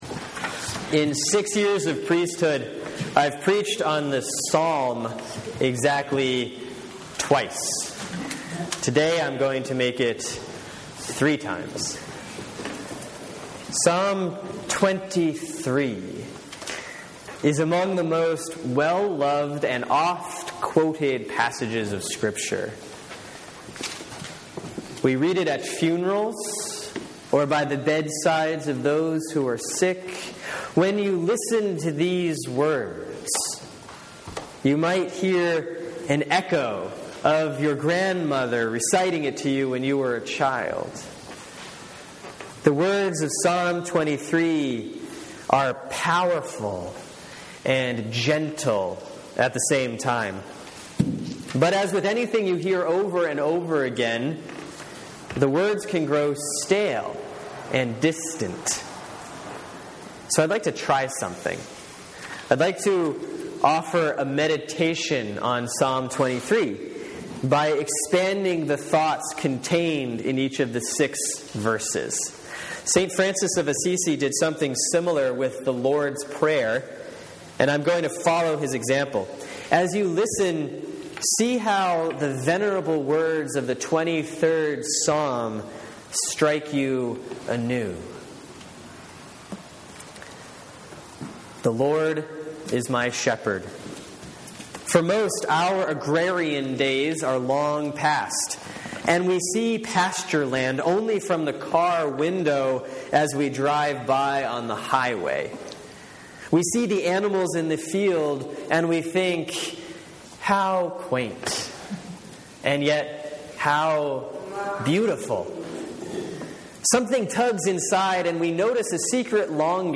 (Sermon for Sunday, May 11, 2014 || Easter 4A || Psalm 23)